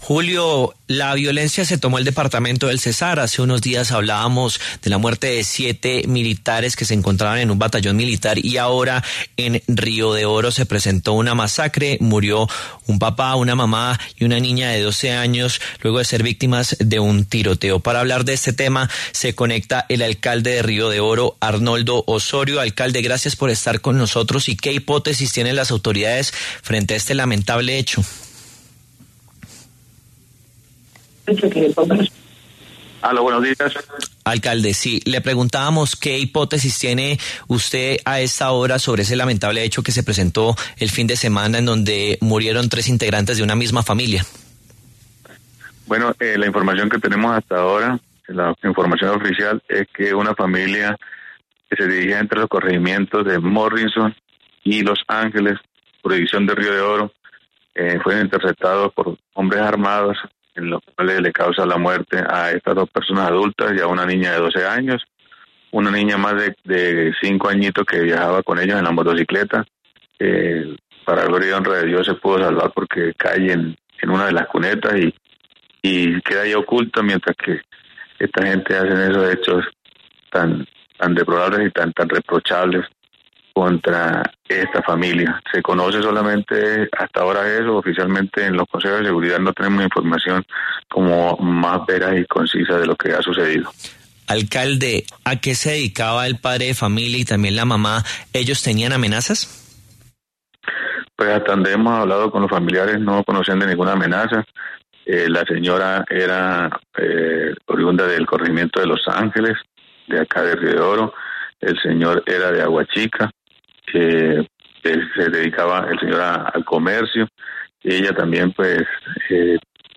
La W conversó con el alcalde de Río de Oro, Cesar, quien dio detalles de cuál es el estado de la niña de 5 años que sobrevivió al ataque.